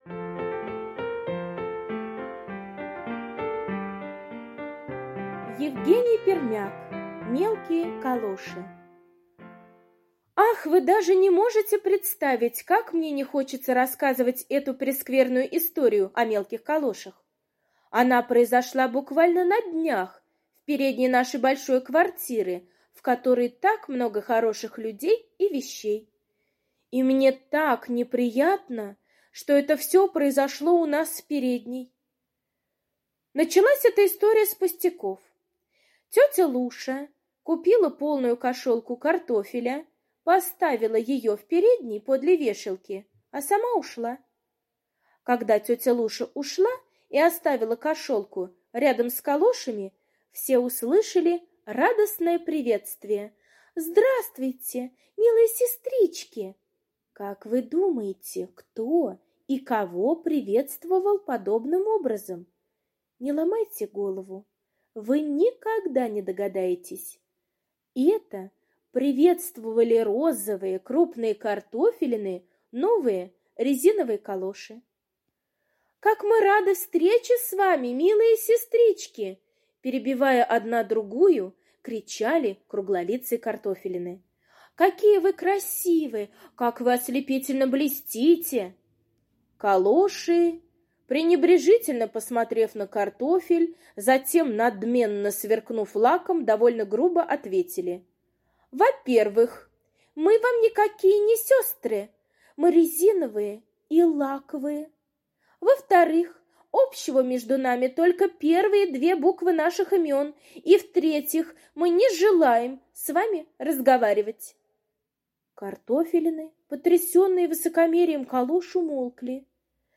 Мелкие калоши — аудиосказка Пермяка Е. Сказка про надменные калоши, которые оказались в передней столичной квартиры и тут же зазнались...